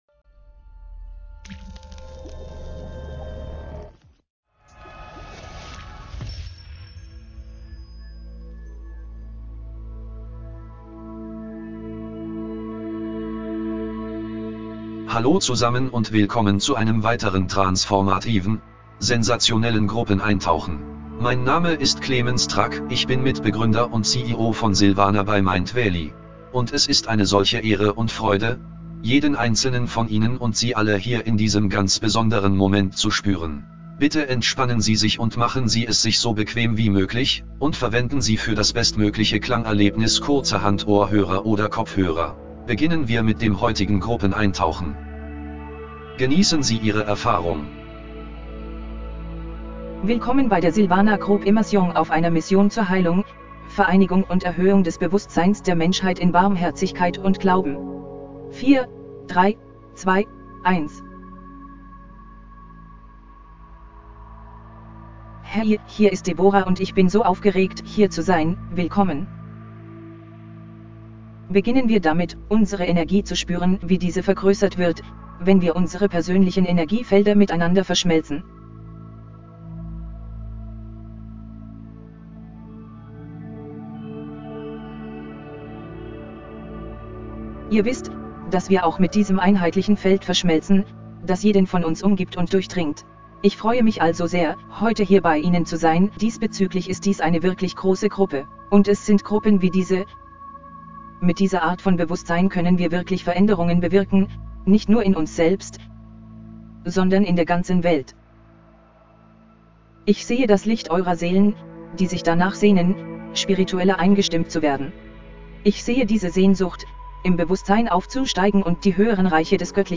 Die Meditationsvideos, die ausgewählt wurden, um den Zugang zu den äußeren Chakras zu erleichtern, verwenden binaurale Beats, die zum Anhören mit Kopfhörern konzipiert sind.